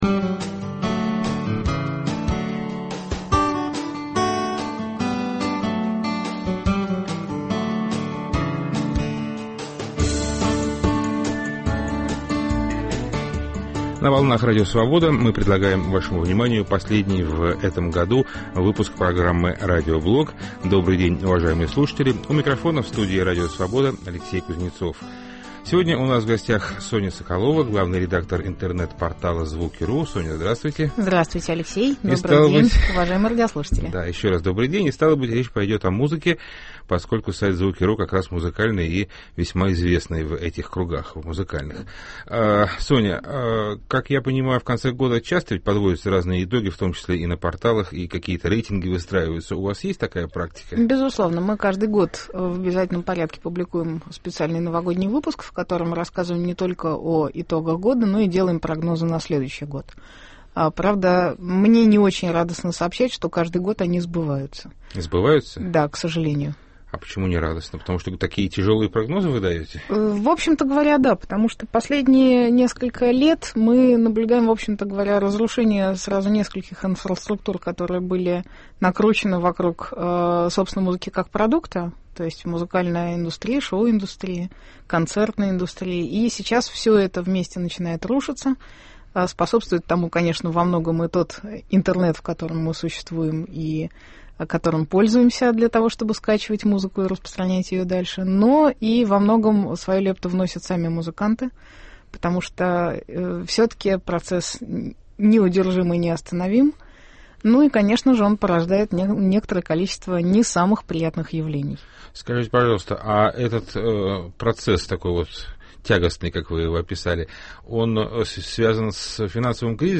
Новогоднее настроение и немного музыки принесет в студию Радио Свобода